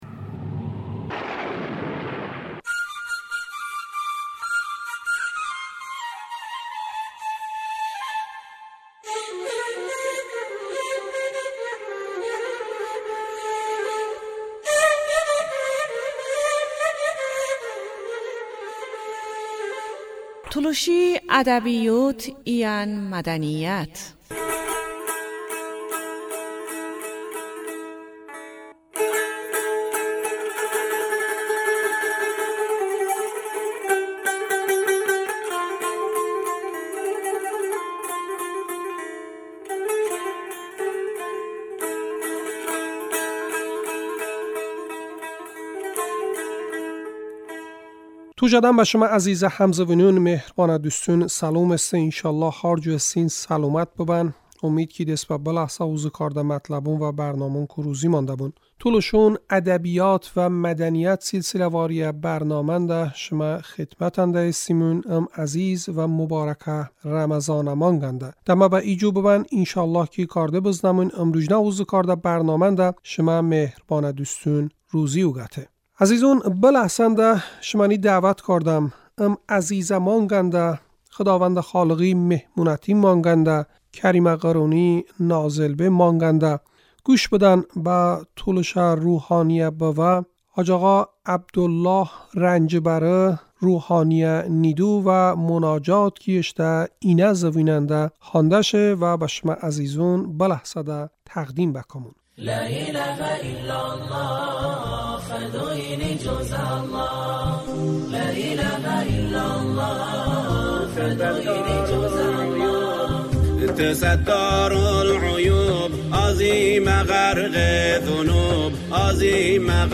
tolışə munacat